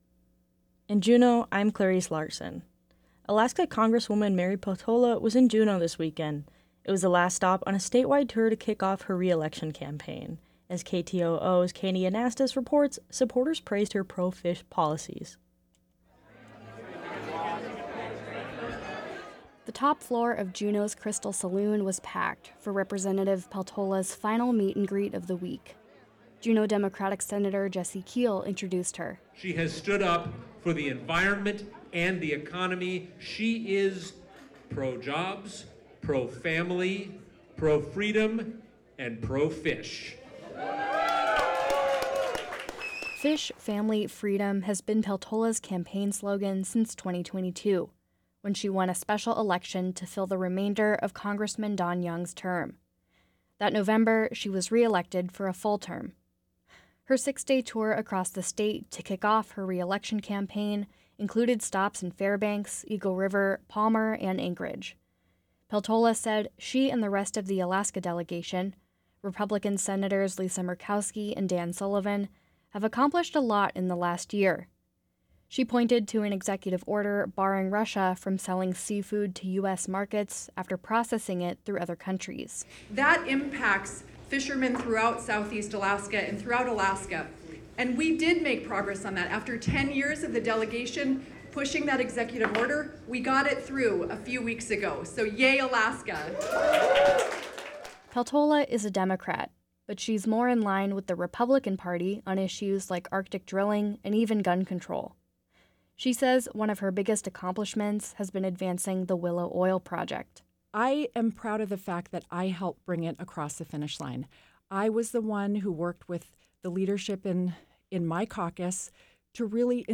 Newscast – Monday, Jan. 29, 2024